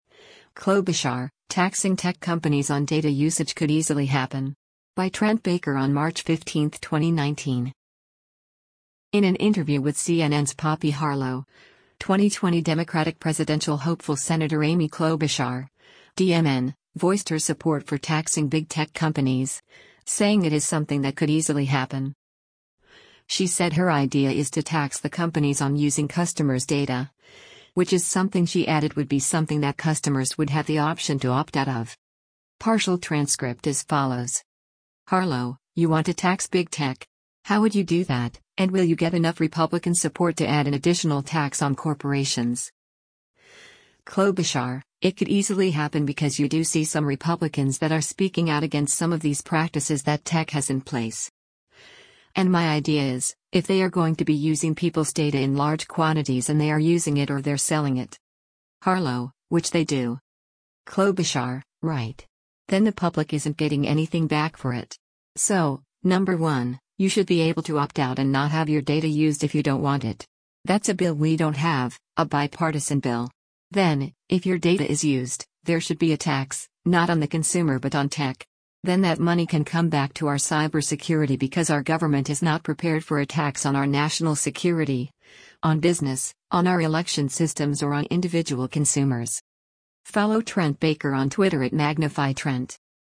In an interview with CNN’s Poppy Harlow, 2020 Democratic presidential hopeful Sen. Amy Klobuchar (D-MN) voiced her support for taxing big tech companies, saying it is something that “could easily happen.”